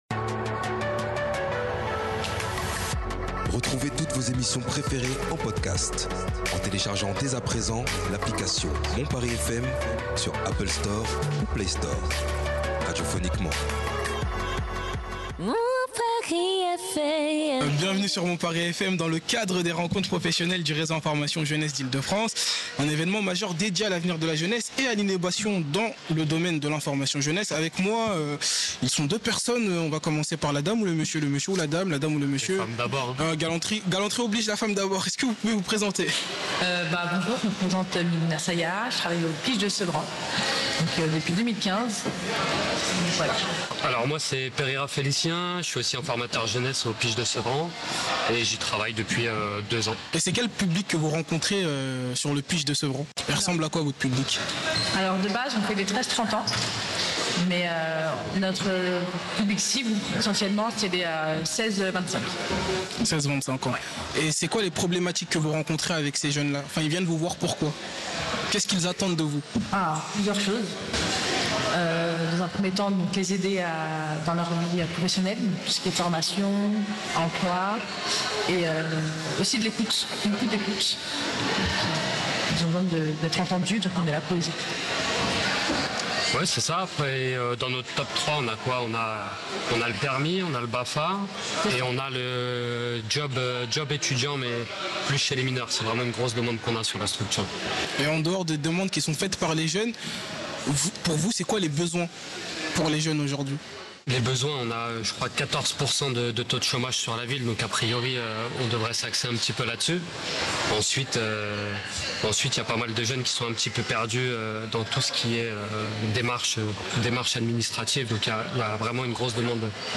Bienvenue dans notre podcast spécial sur le séminaire des informateurs jeunesse réalisé par le CIDJ, en octobre dernier.